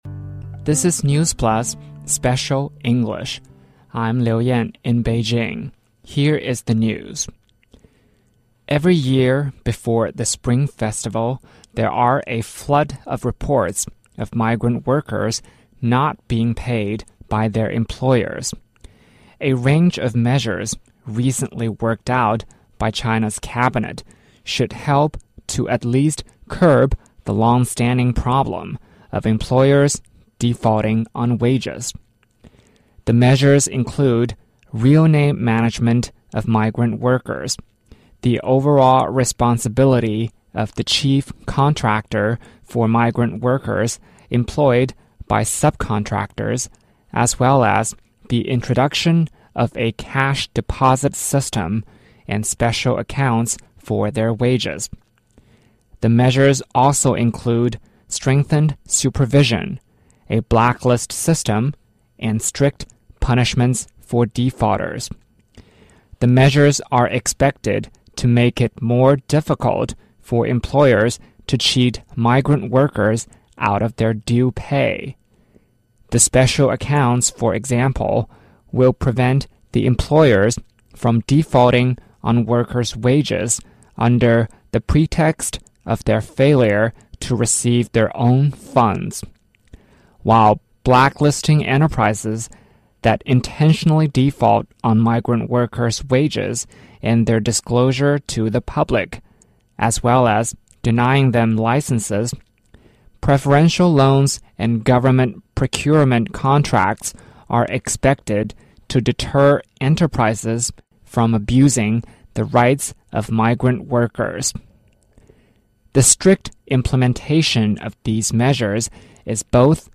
News Plus慢速英语:国务院发文全面治理拖欠农民工工资问题 我国居民健康水平处于中高收入国家平均水平